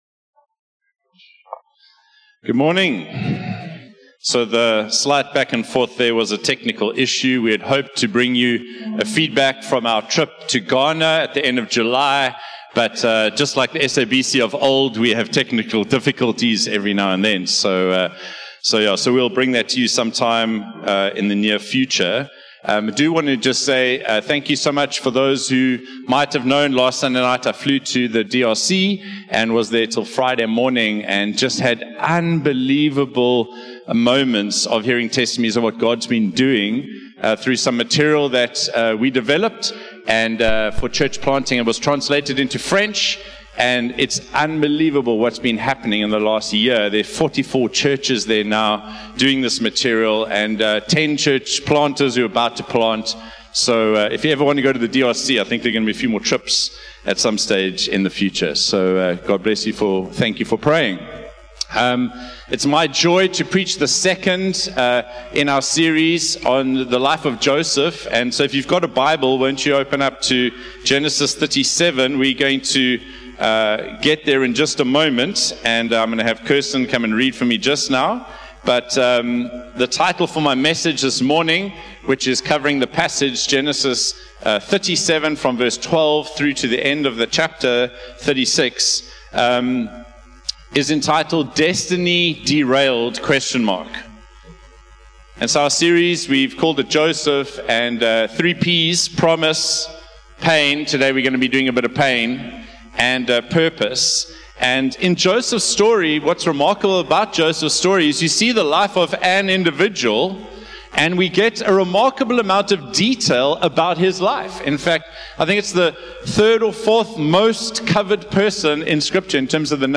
One-Hope-Sermon-1-September-2024.mp3